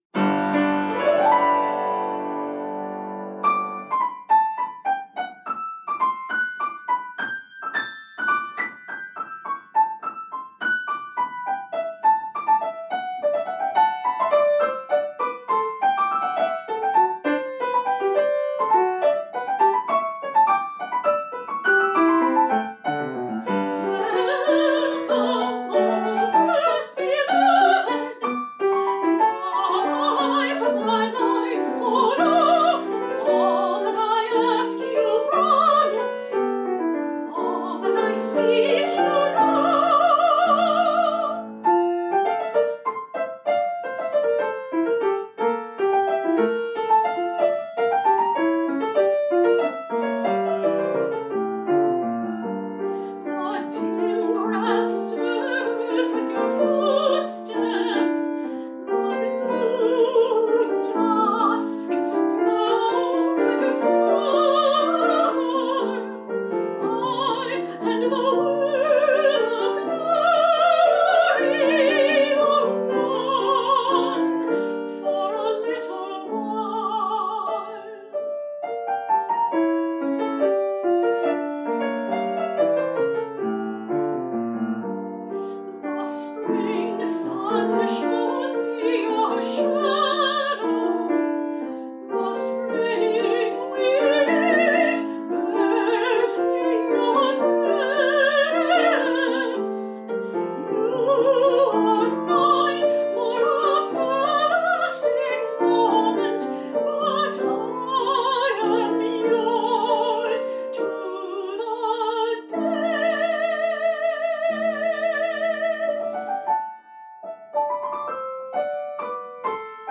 for medium high voice & piano